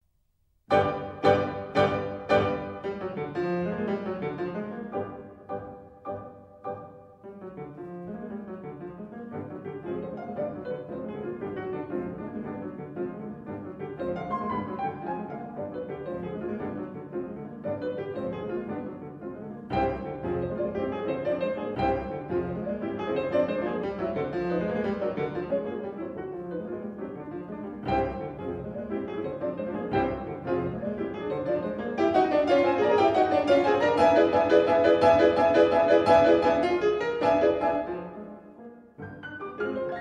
String Quartet in G minor
Assez vif et bien rythmé